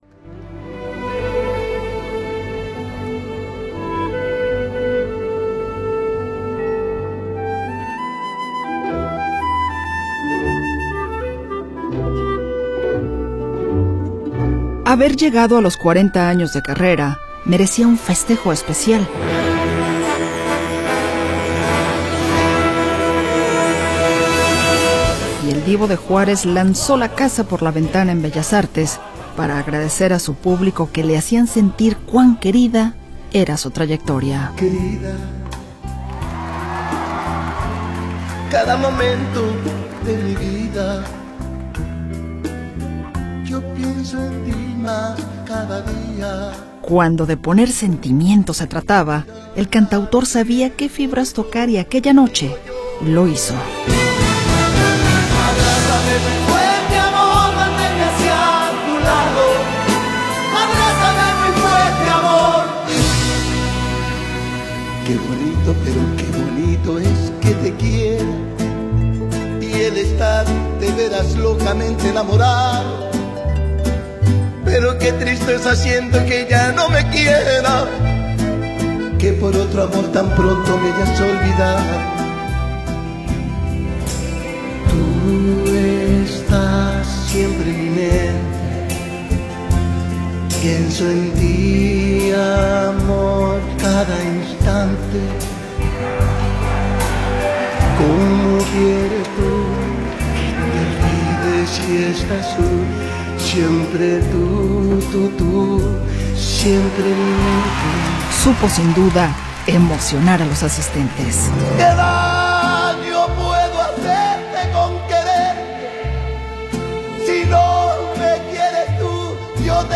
El concierto fue grabado íntegramente en 4K transformando así a Juan Gabriel como el primer artista latino en grabar en dicho formato, se utilizaron 185 micrófonos, 172 canales simultáneos de audio y 15 cámaras.